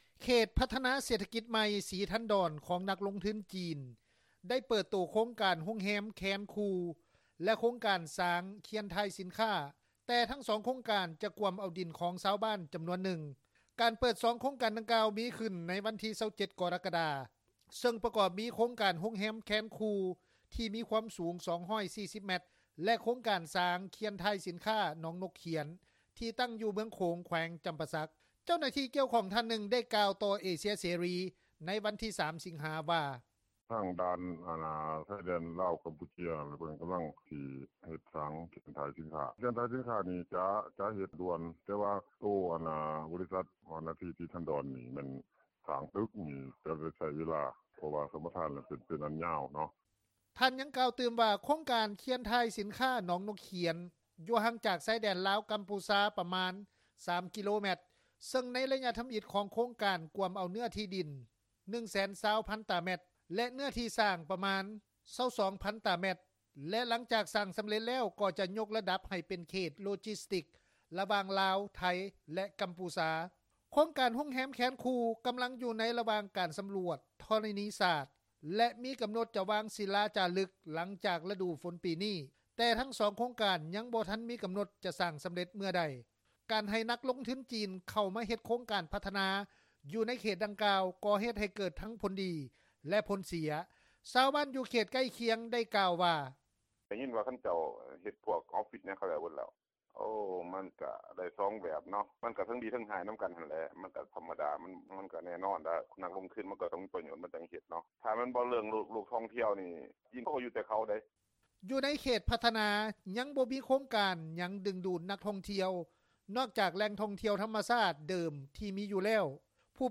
ຊາວບ້ານ ຢູ່ເຂດໃກ້ຄຽງ ໄດ້ກ່າວວ່າ:
ຊາວບ້ານທີຢູ່ໃກ້ຄຽງ ໂຄງການ ອີກທ່ານນຶ່ງ ໄດ້ກ່າວວ່າ: